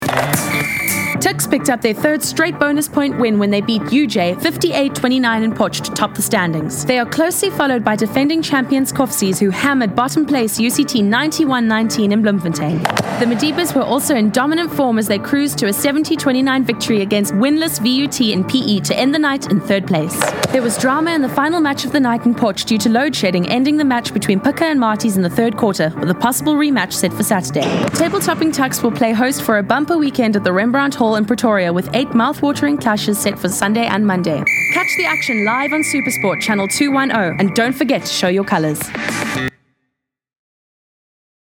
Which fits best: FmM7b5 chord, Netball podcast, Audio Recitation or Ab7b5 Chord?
Netball podcast